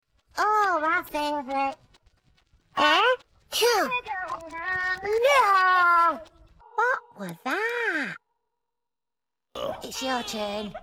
음성 부분